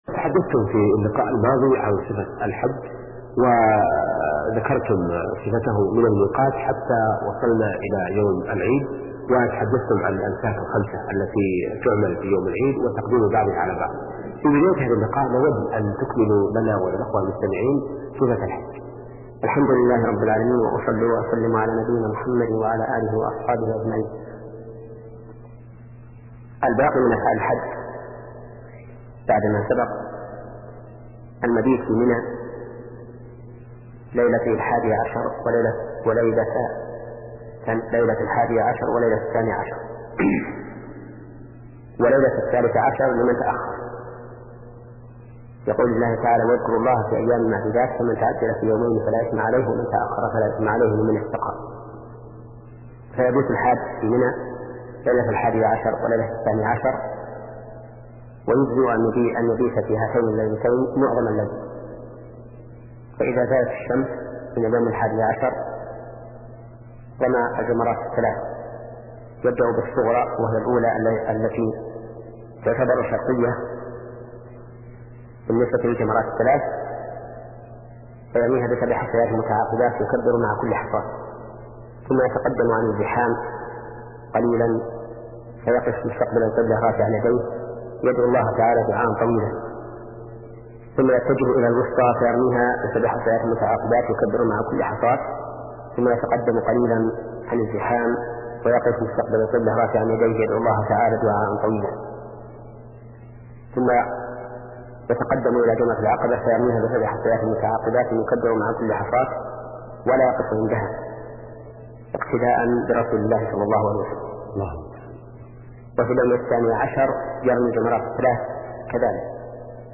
شبكة المعرفة الإسلامية | الدروس | فقه العبادات (40) |محمد بن صالح العثيمين
فقه العبادات [40] - للشيخ : ( محمد بن صالح العثيمين ) يشترك الحج والعمرة بثلاثة أركان هي: الإحرام والطواف والسعي، ويزاد في الحج: الوقوف بعرفة، وأما الإحرام من الميقات، أو المبيت بمنى، أو المزدلفة ليلة العيد، وكذا الرمي والذبح والحلق فواجبات، من فرط فيها متعمداً أثم، ويلزمه عامداً كان أو جاهلاً.